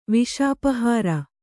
♪ viṣāpahāra